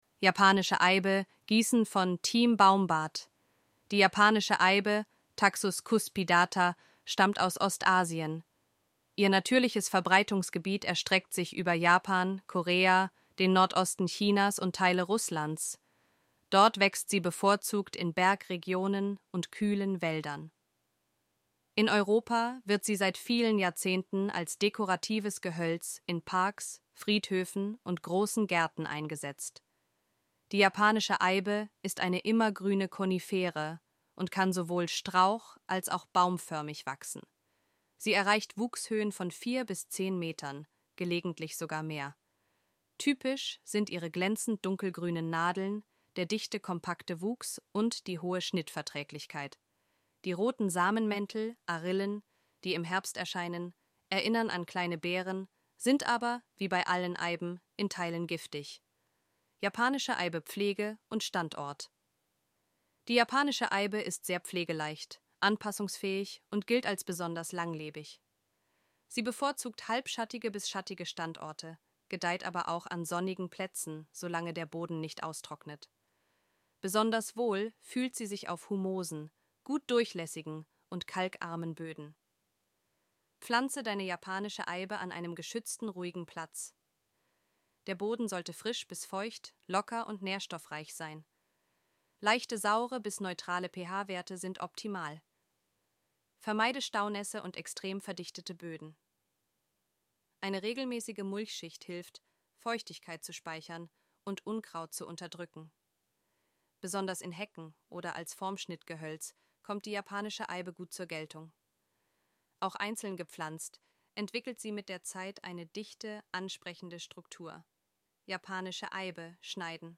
Artikel vorlesen